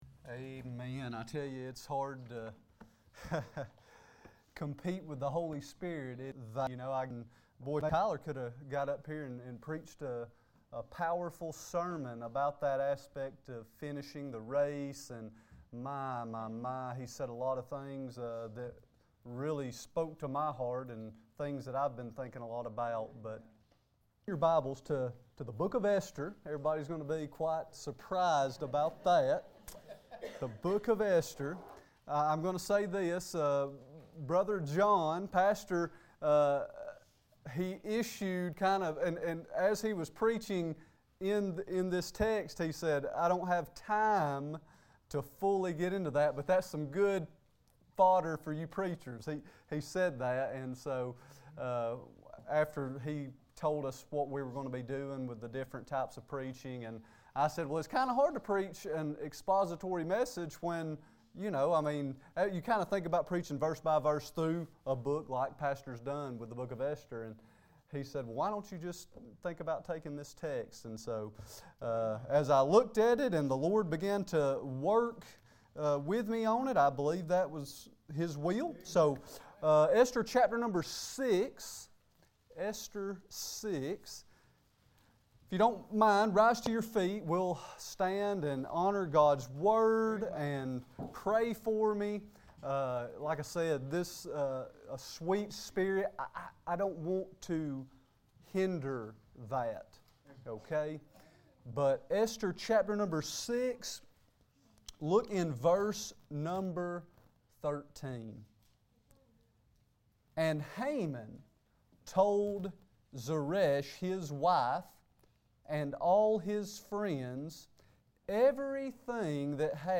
Passage: Esther 6:13 Service Type: Sunday Evening Next Sermon